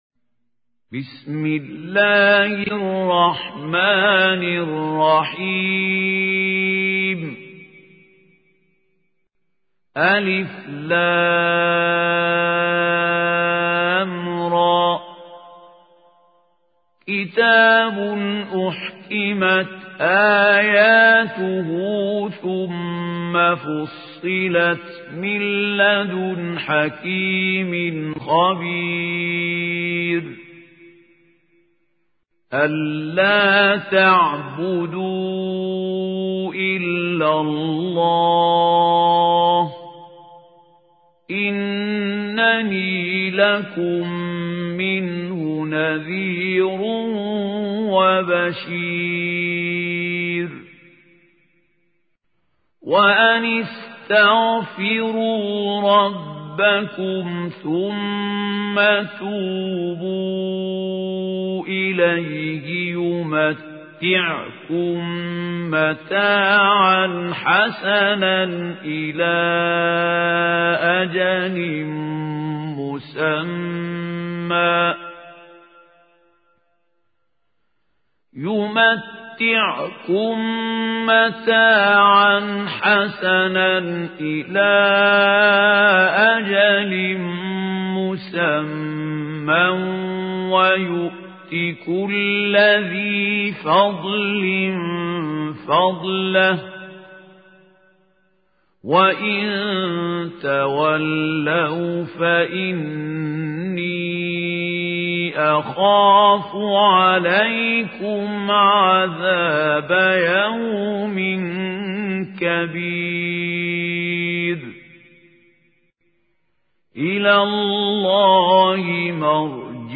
القارئ: الشيخ خليل الحصري